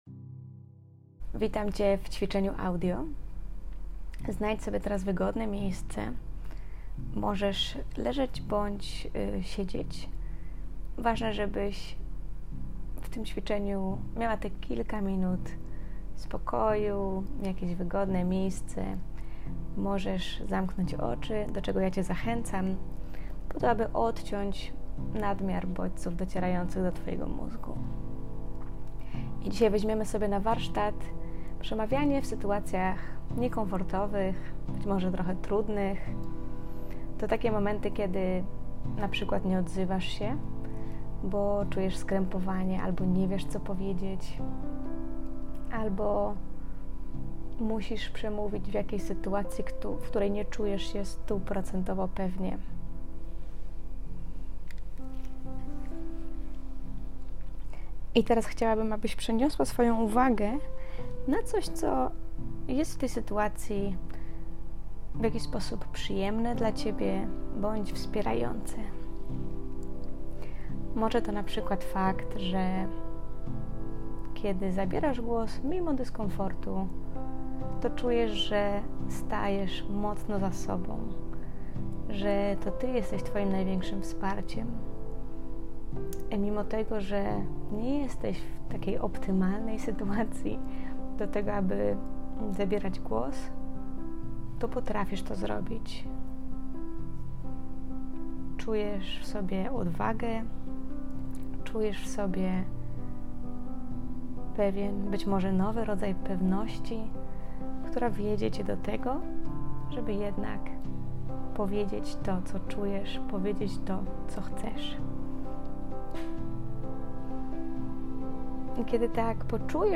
Ćwiczenie audio
Trening-Pozytywnej-Neuroplastycznosci-cwiczenie.mp3